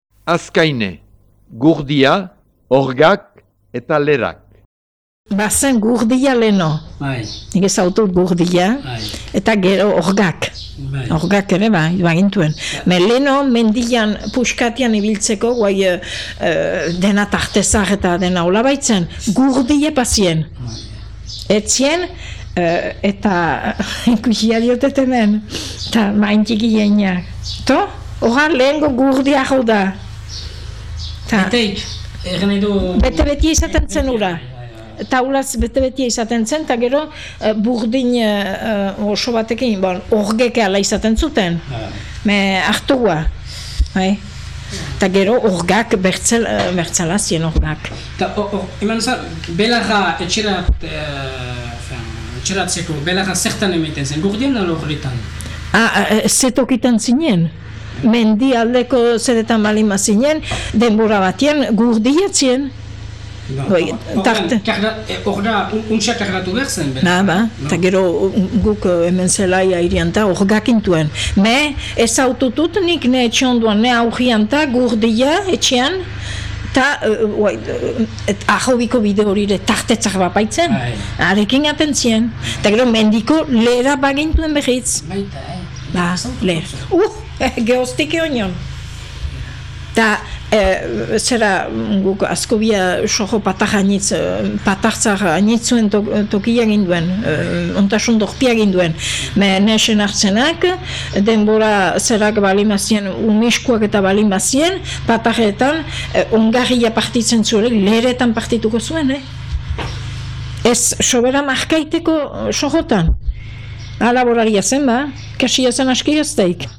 Azkaine.mp3